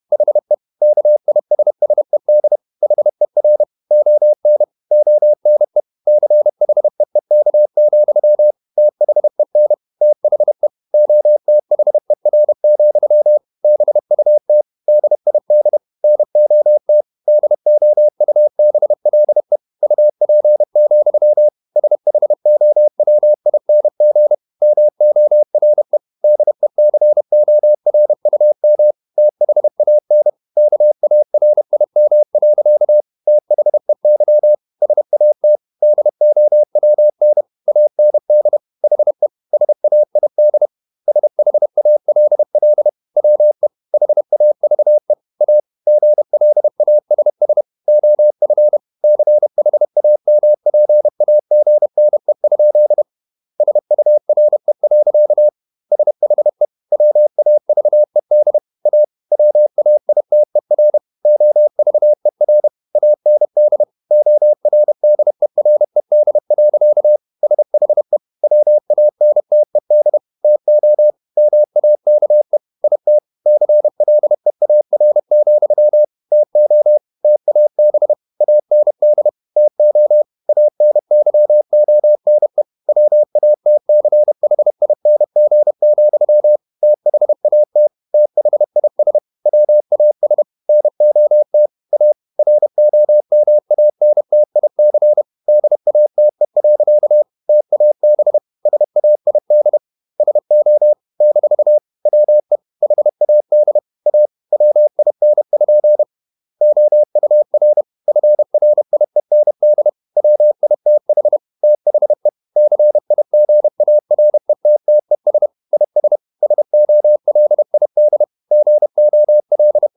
Never 31wpm | CW med Gnister